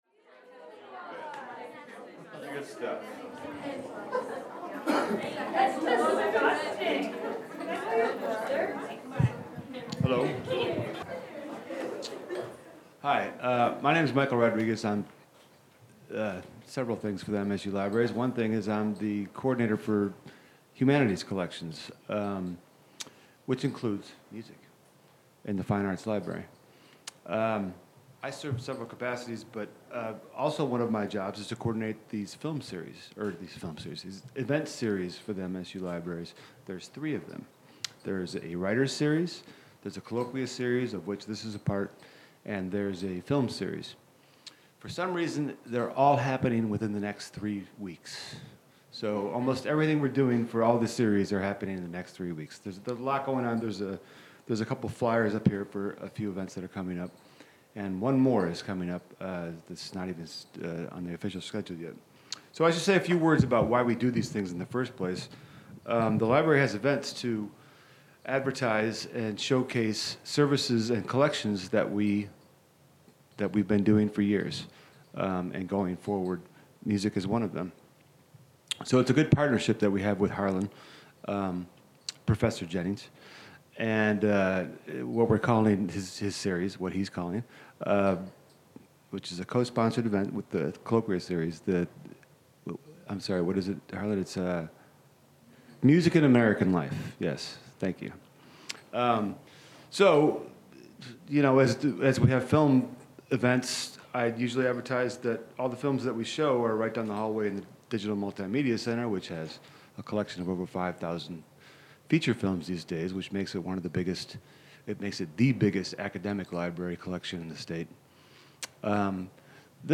She also describes Dett materials held in the MSU Archives and other collections. She answers questions from the audience.
Part of the Michigan State University Libraries' Colloquia series. Held at the Michigan State University Main Library.